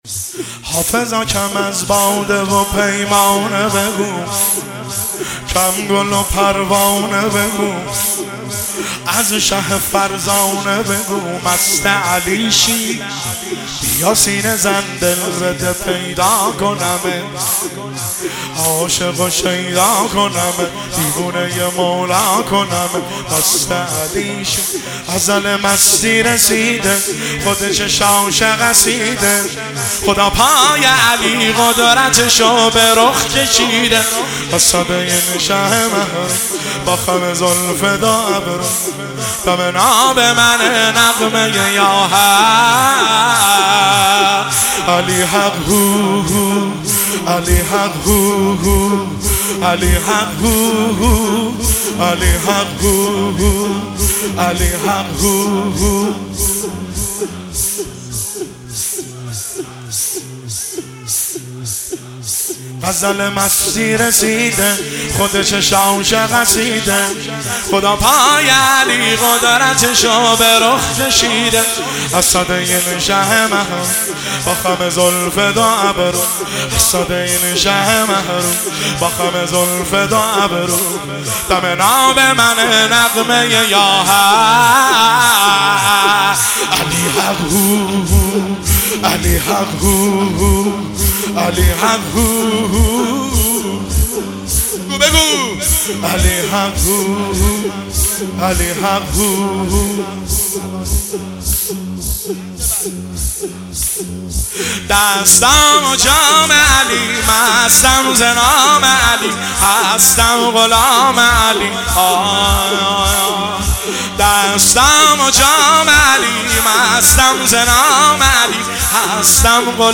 تک - شب سوم محرم الحرام 1404